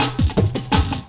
35LOOP01SD-L.wav